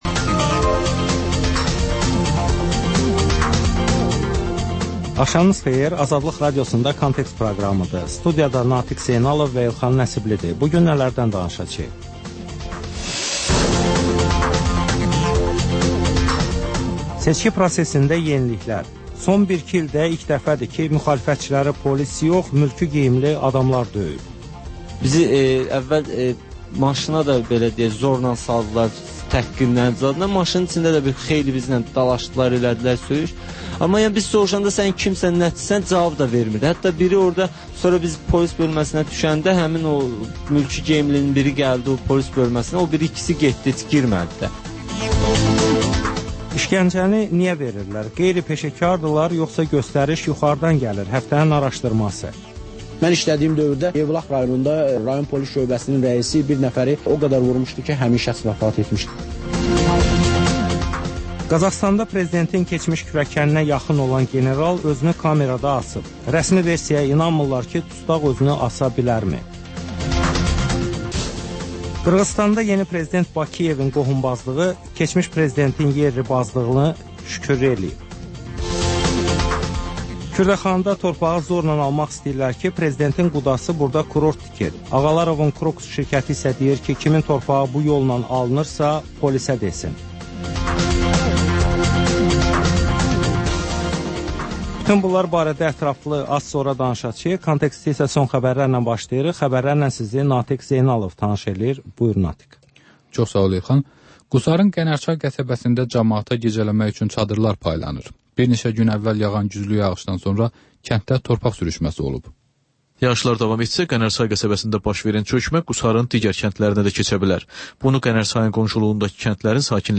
Xəbərlər, müsahibələr, hadisələrin müzakirəsi, təhlillər, sonda isə HƏMYERLİ rubrikası: Xaricdə yaşayan azərbaycanlıların həyatı